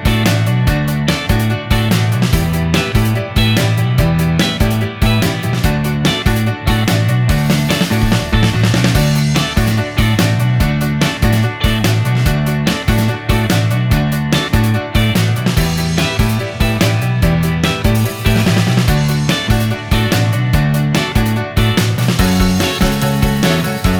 no Backing Vocals Indie / Alternative 3:04 Buy £1.50